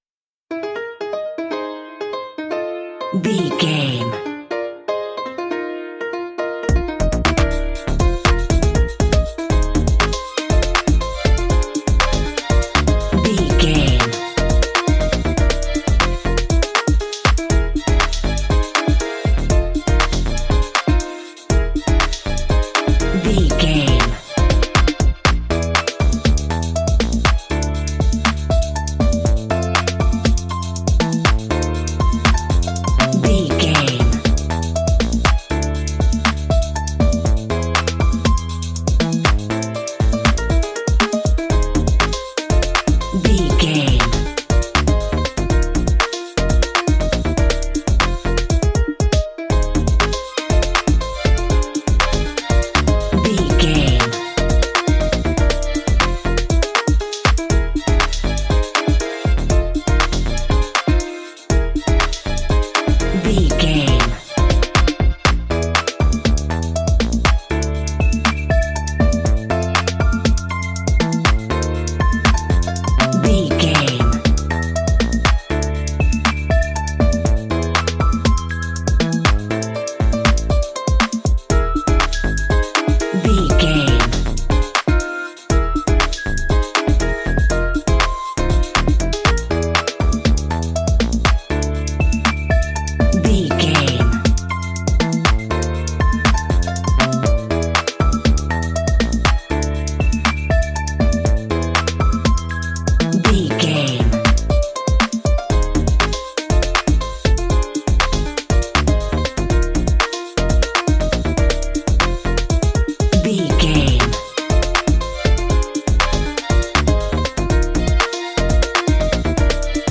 Fast paced
Ionian/Major
Fast
cheerful
lively
festive
energetic
bass guitar
violin
drums
electric piano
synthesiser